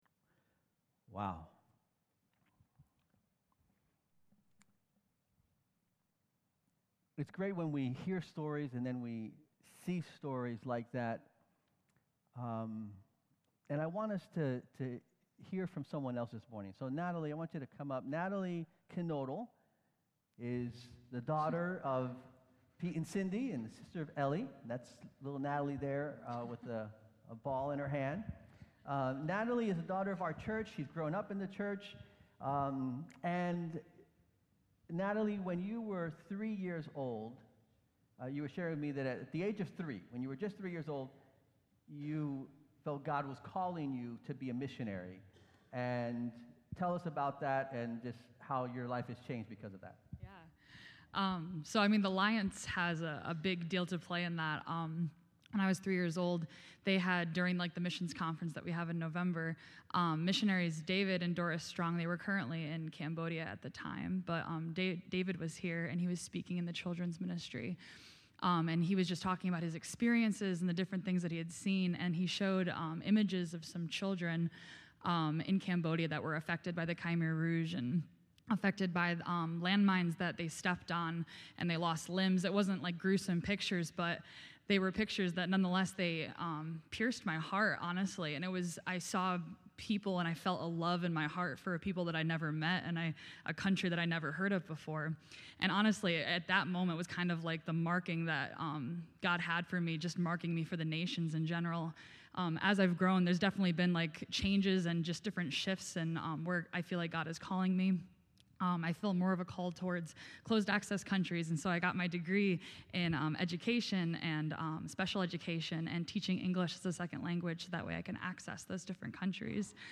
A message from the series "Spiritual Gifts."